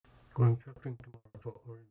Also keep the volume down very low at first for guessing and then increase the volume to see if you got it right.
fade out recording